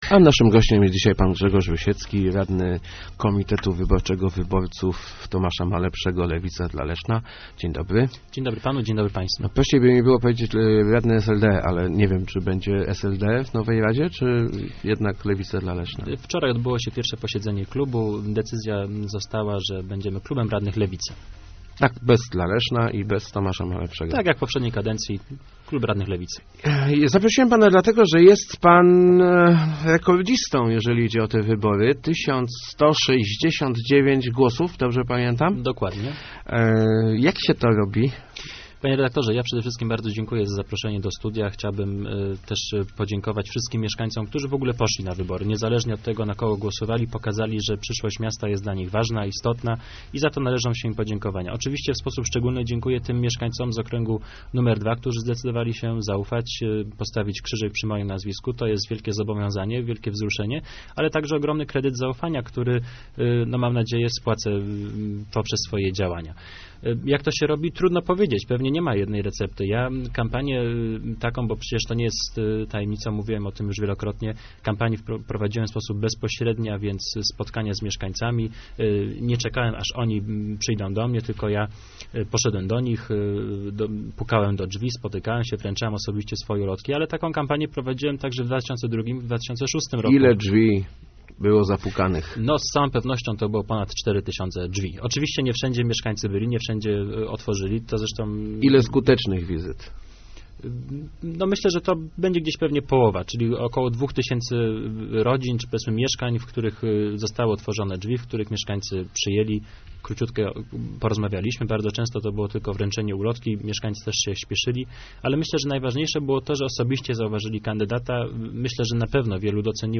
rusiecki80.jpgJeżeli za cztery lata Lewica na mnie postawi, nie wykluczam startu w wyborach na prezydenta - mówił w Rozmowach Elki Grzegorz Rusiecki, zdobywca najlepszego wyniku w niedzielnych w yborach w Lesznie. Ujawił też kilka szczegółów powyborczych strategii swojego ugrupowania.